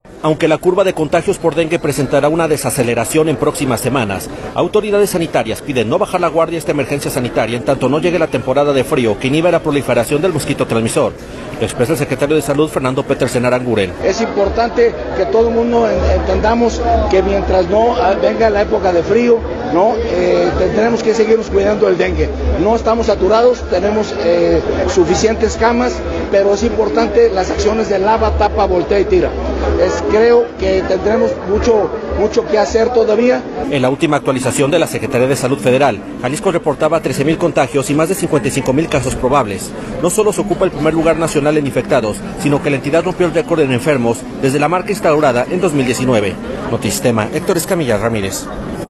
Aunque la curva de contagios por dengue presentará una desaceleración en próximas semanas, autoridades sanitarias piden no bajar la guardia a esta emergencia sanitaria en tanto no llegue la temporada de frío, que inhibe la proliferación del mosquito transmisor. Lo expresa el secretario de Salud, Fernando Petersen Aranguren.